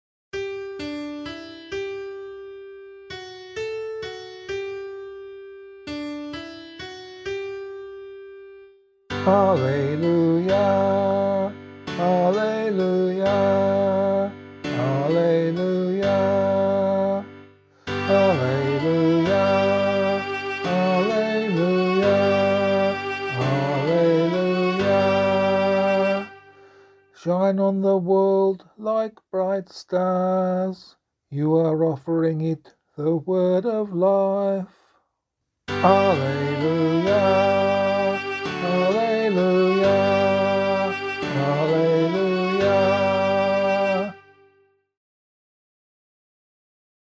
Gospel Acclamation for Australian Catholic liturgy.